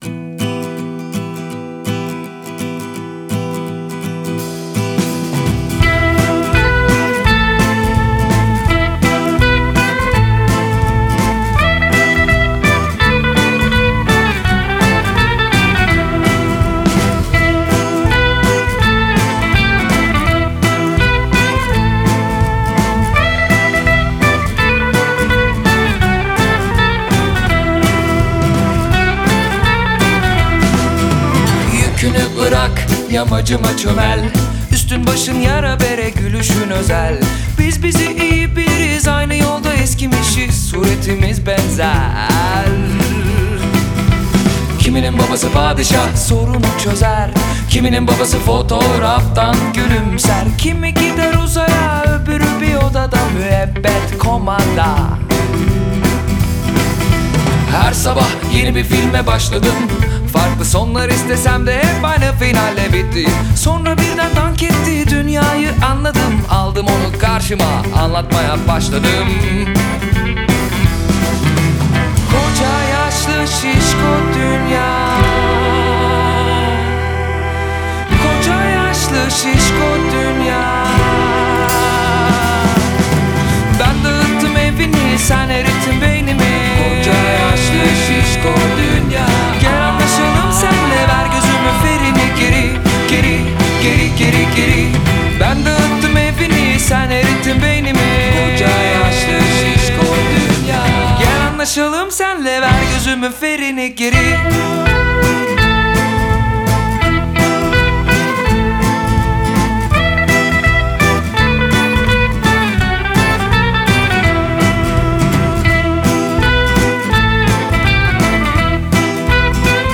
Turkish Rock, Indie Rock https